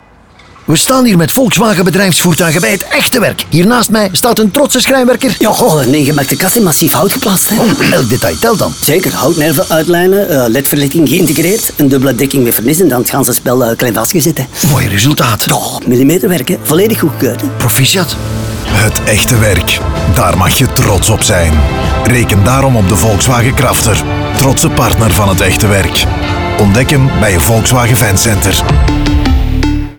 En ook op radio staan de trotse vakmensen centraal in verschillende reeksen van 3 radiospots waarin vakmensen met trots over hun job praten als waren het fiere sportmannen na een topprestatie.
Schrijnwerker_NL.mp3